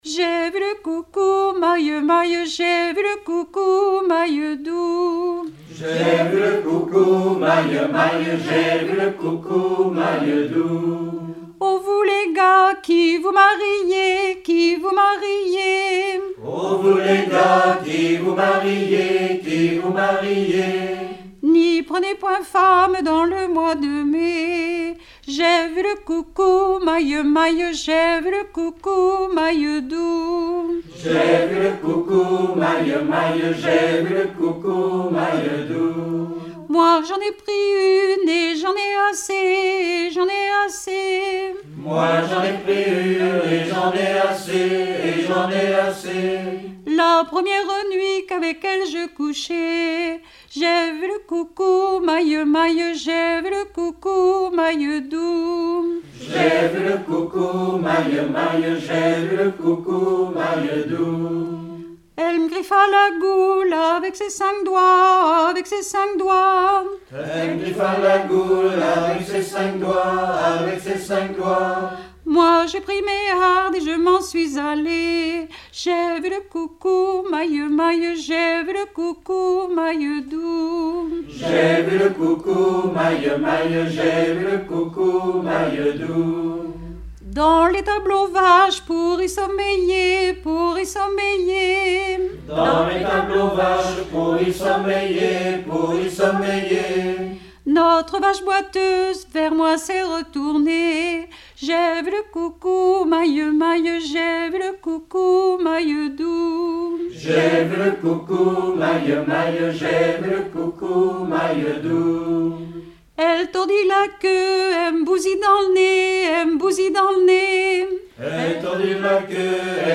Genre laisse
Collectif de chanteurs du canton - veillée (2ème prise de son)
Pièce musicale inédite